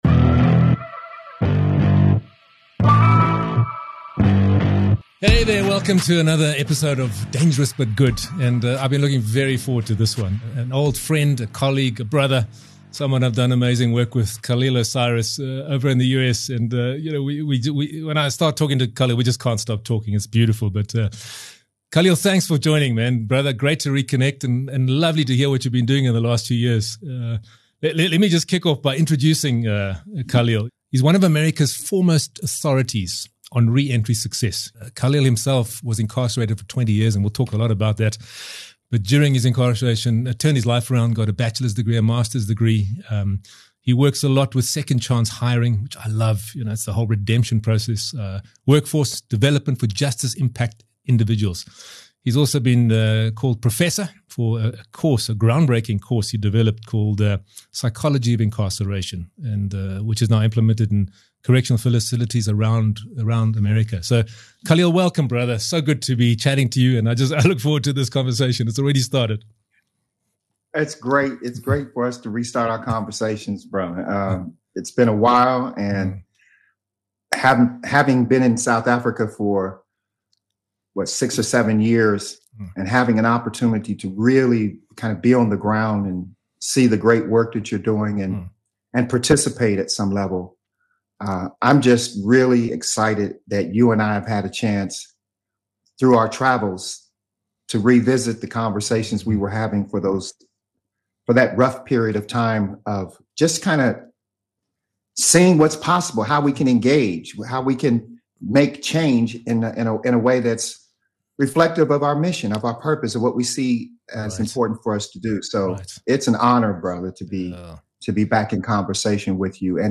In this conversation, we tackle some of the pressing issues of our time—race, cancel culture, virtue signaling, tribalism, and the labels that so often divide us. But more than that, we explore the power of owning your story, breaking free from the past and stepping into a life of purpose.